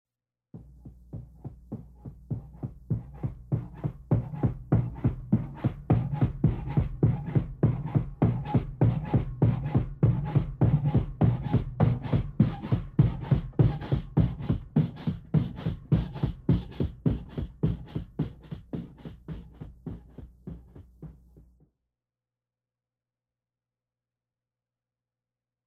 100 Sound Effects Vol.3 - 28 - Pogo Stick - Madacy Music Group Inc.
Tags: radio funny sounds